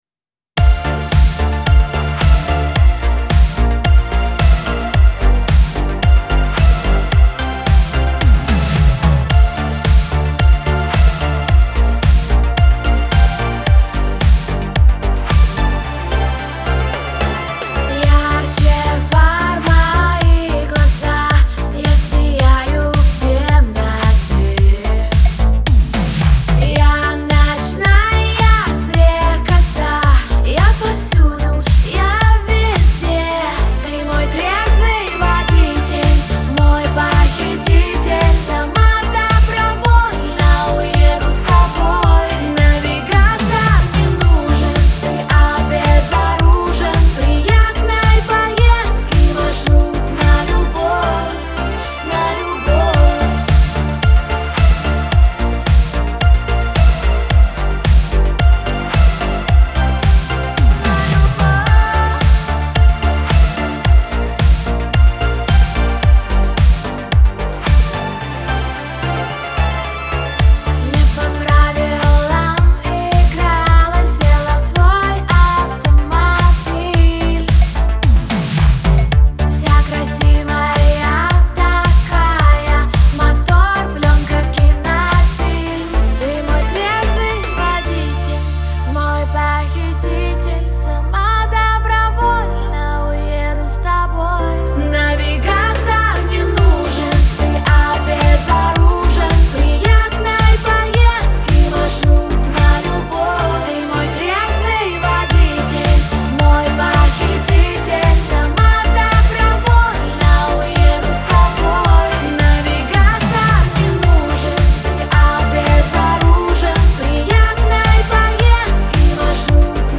харизматичным вокалом и запоминающимися мелодиями